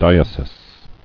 [di·e·sis]